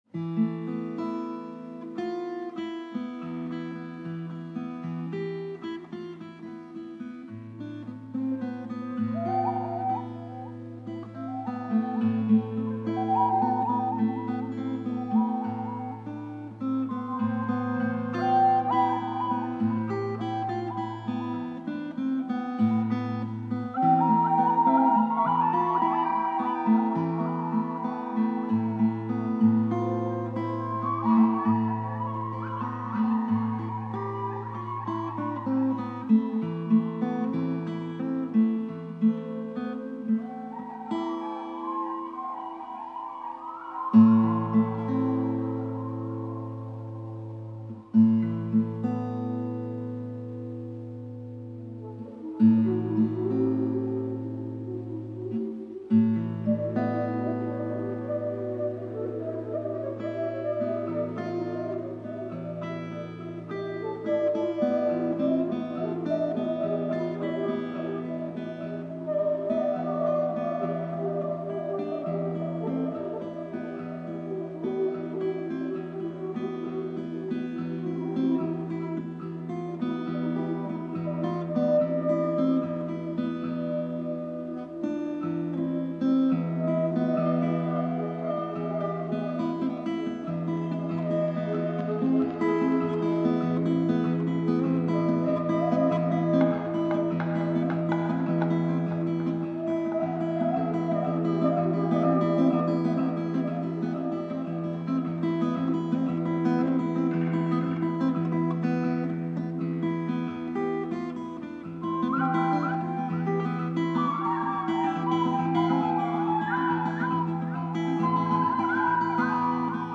Musica Psichedelica / Folk / Ambiente